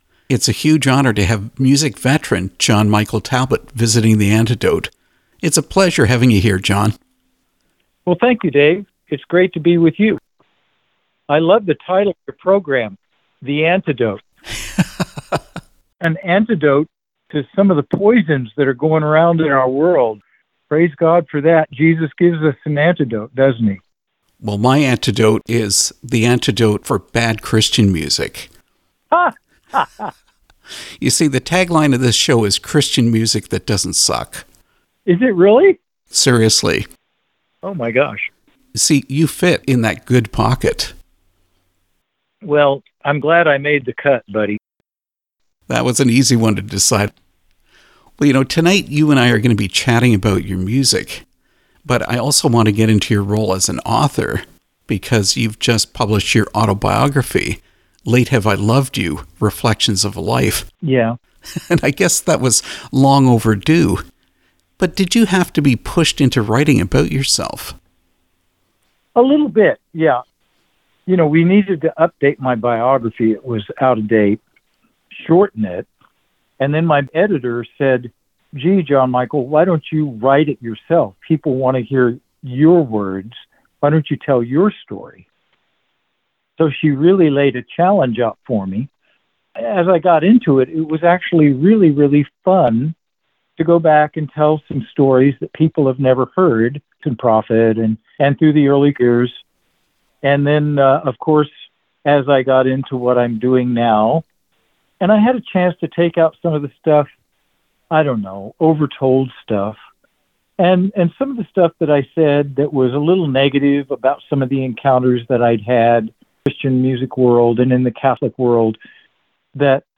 Interview with John Michael Talbot
john-michael-talbot-interview.mp3